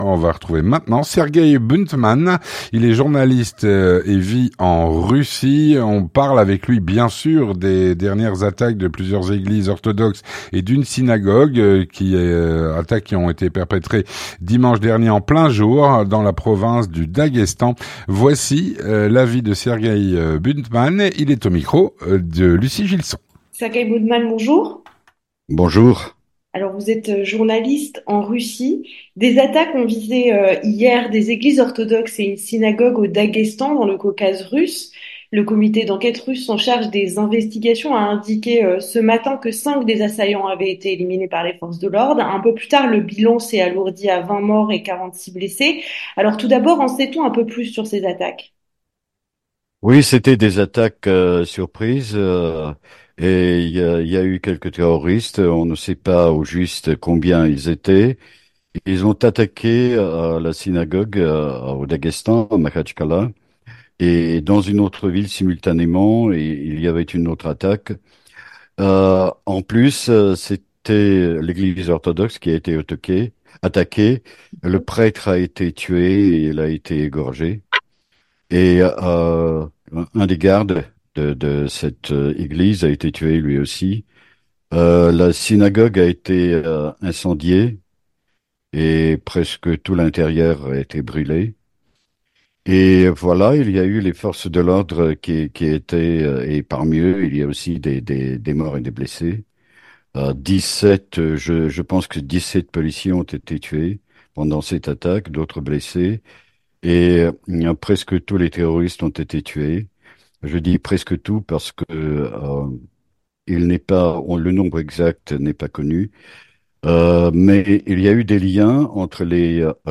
journaliste en Russie.